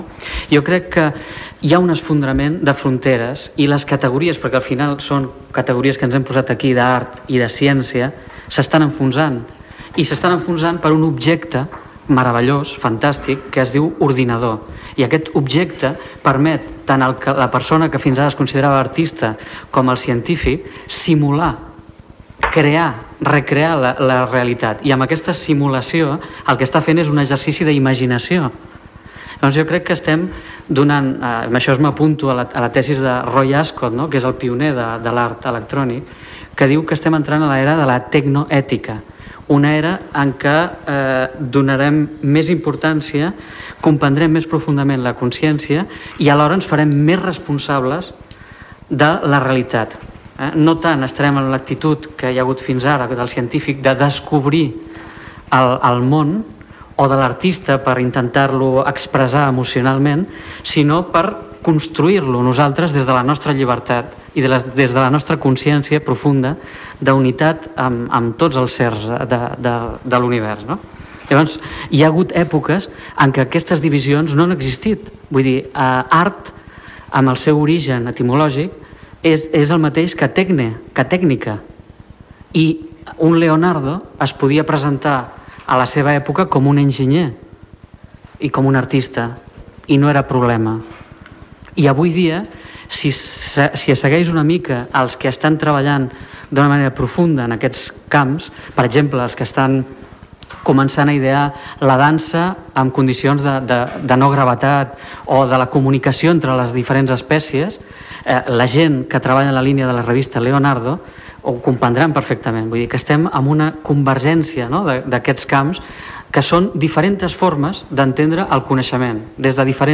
Enregistrat, cara al públic, el dia 3 de juny de 1998, al Centre Cultural de la Fundació La Caixa de Barcelona.